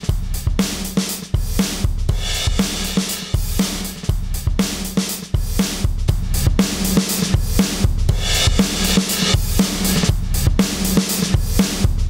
Взял кусок из вашего МР3 видео, применил по-быстрому, но на слух.